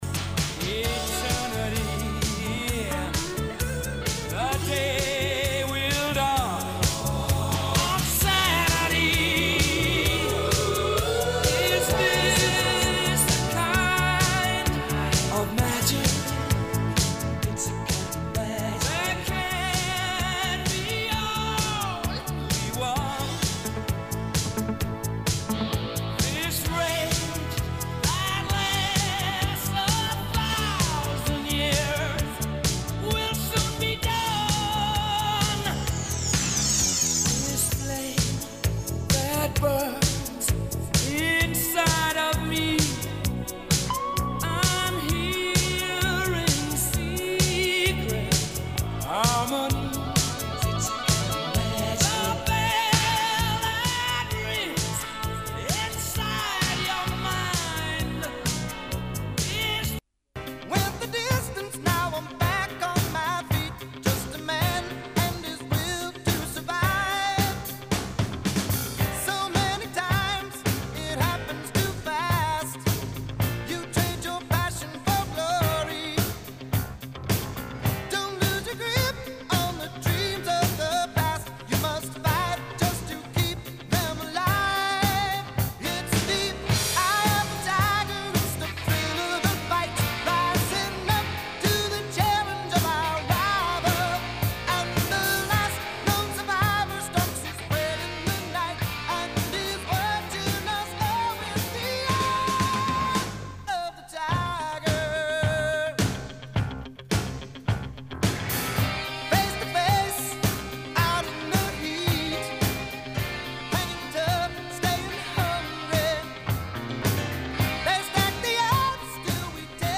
Pop-Rock